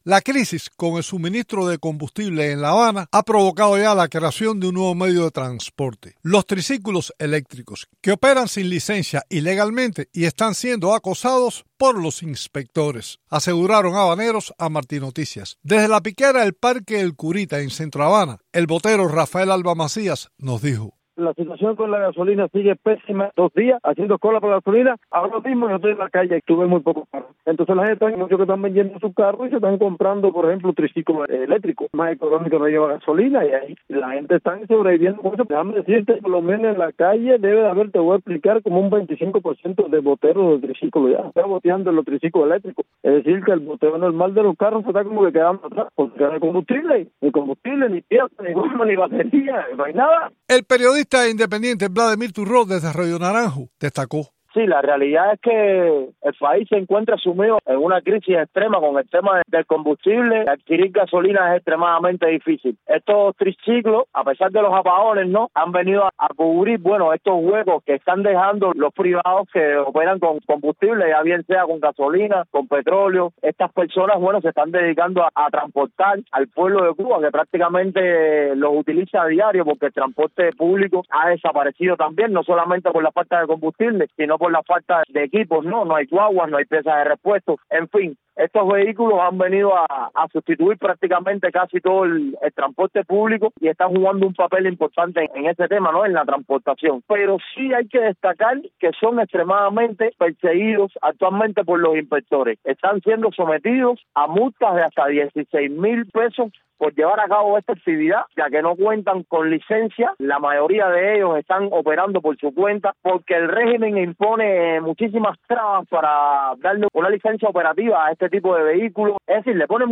La crisis con el suministro de combustible en La Habana ha puesto de moda un nuevo medio de transporte, los triciclos eléctricos, que operan sin licencia, de forma ilegal, y están siendo acosados por los inspectores, aseguraron habaneros entrevistados por Martí Noticias.